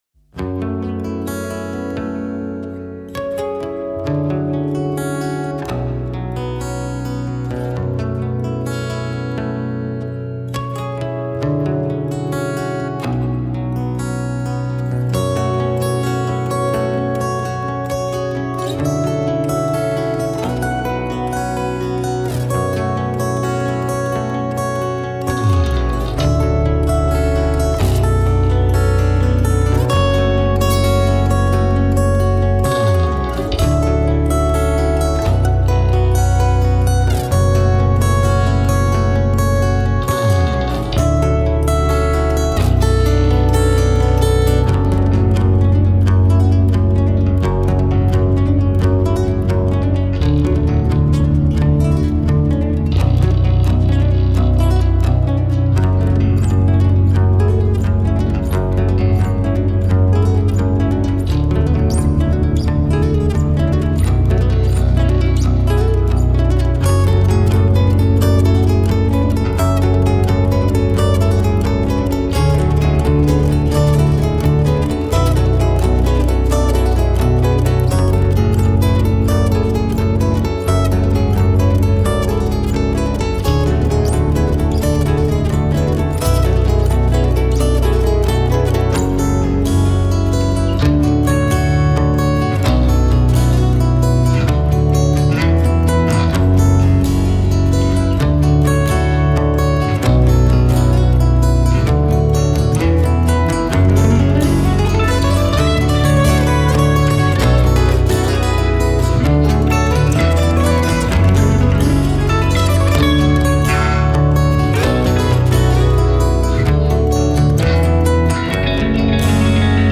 Игра_на_гитаре_-_Инструментал_1
Igra_na_gitare___Instrumental_1.mp3